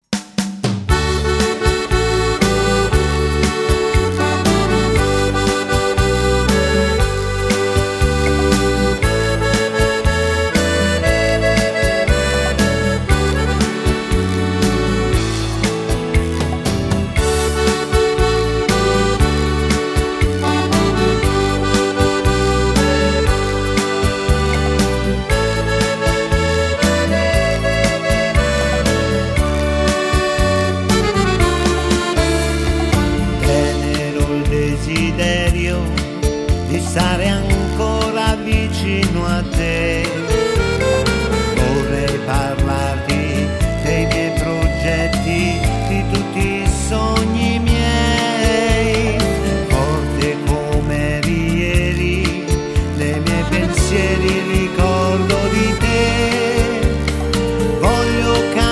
Ritmo allegro
Fisa
Chitarra
studio di registrazione k2 recording studio.